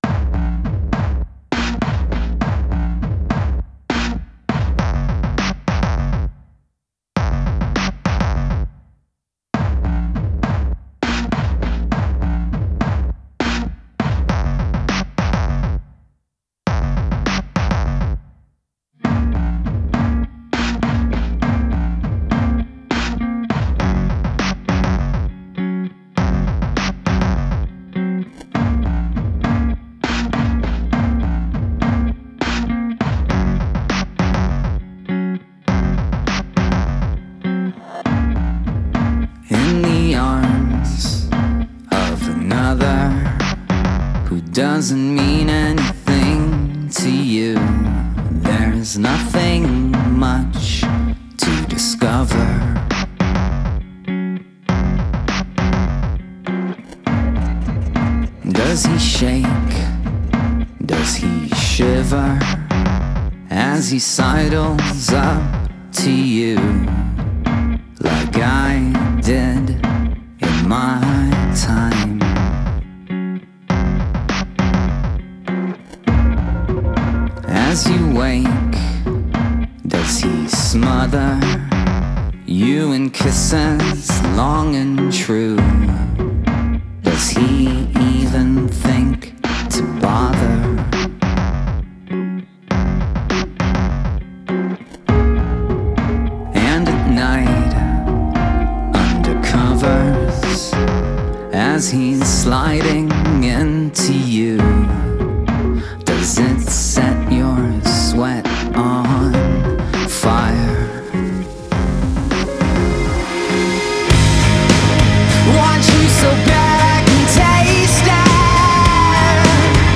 Alternative rock Indie rock Rock